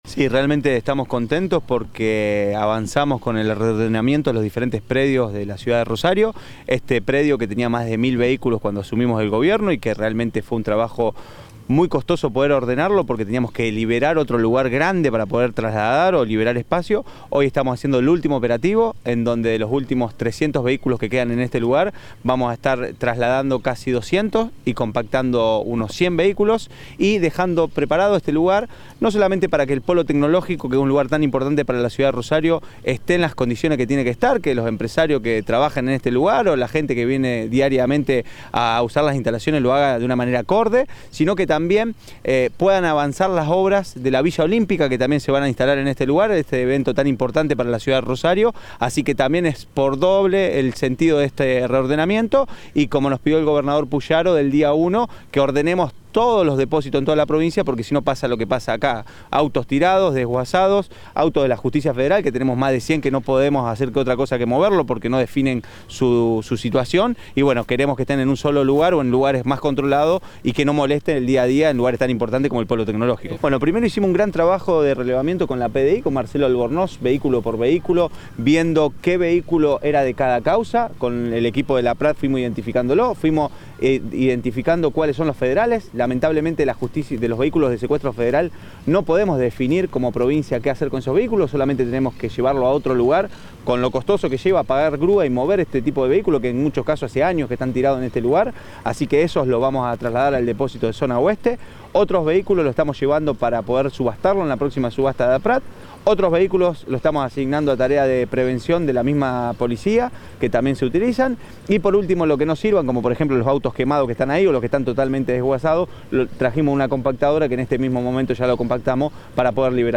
Durante una conferencia de prensa, mientras se realizaba el operativo, el secretario de Gestión de Registros, Matías Figueroa Escauriza, explicó: “Estamos concretando el último operativo de reordenamiento de los diferentes predios de la ciudad. Este predio tenía más de 1.000 vehículos cuando asumimos la gestión, lo cual motivó que liberáramos otro lugar amplio para poder trasladarlos”.
Secretario de Gestión de Registros, Matías Figueroa Escauriza